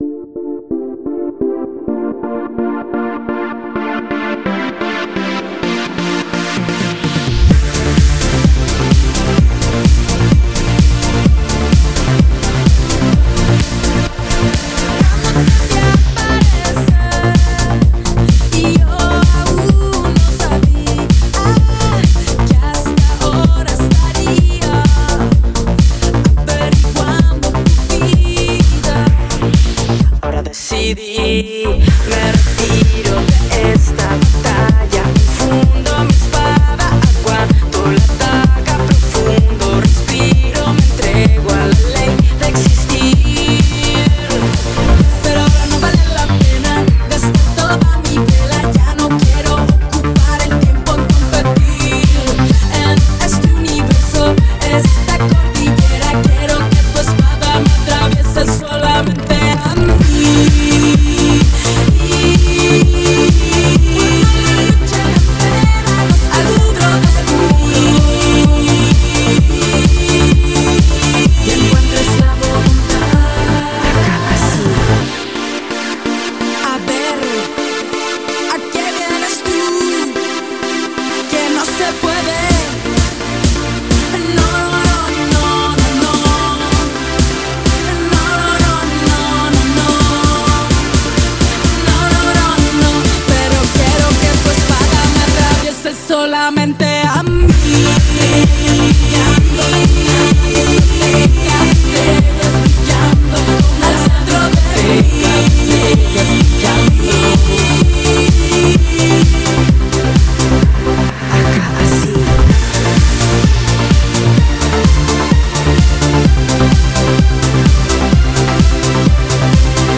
BPM128
Trance remix